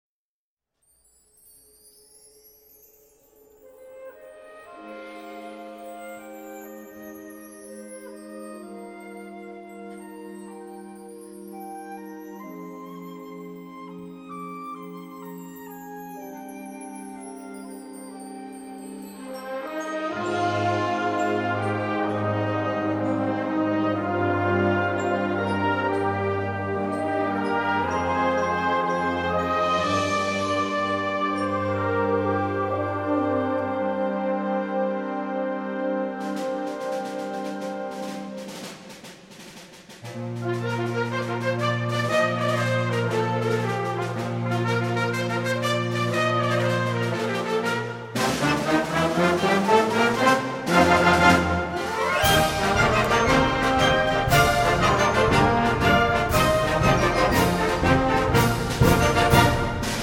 Wind Symphony
With: Wind Symphony